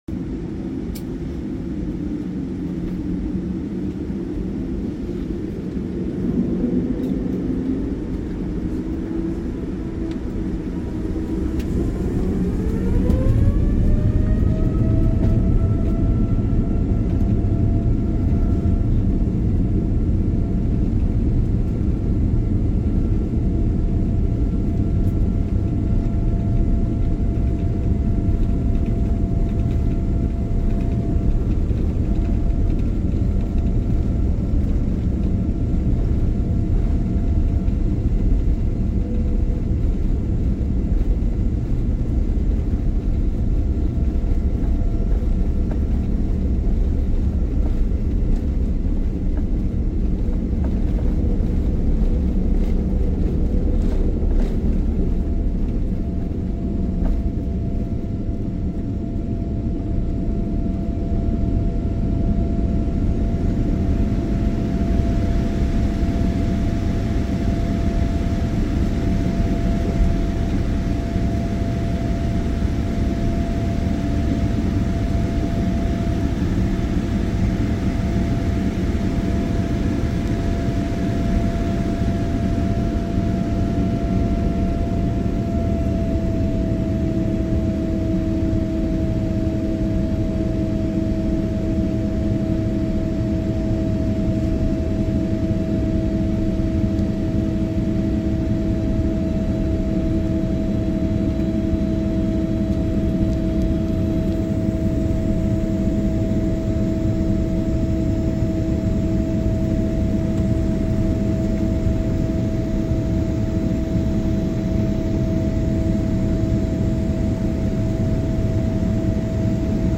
B78X VNA take off ✈ sound effects free download